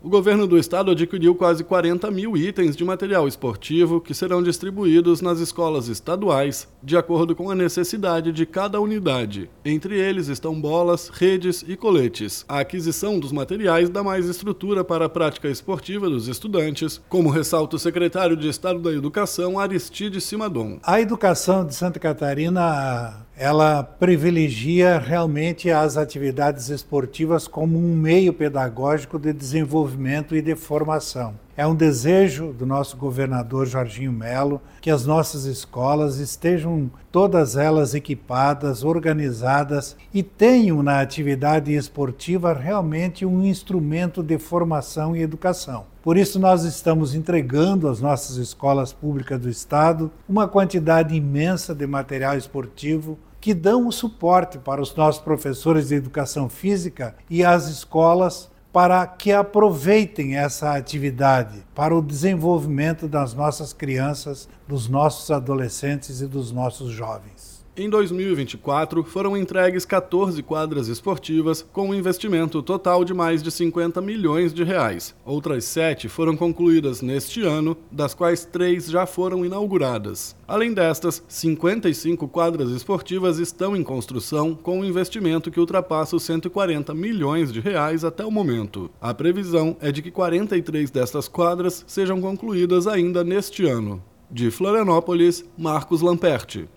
BOLETIM – Governo de SC investe no esporte nas escolas estaduais com mais materiais esportivos e quadras
Repórter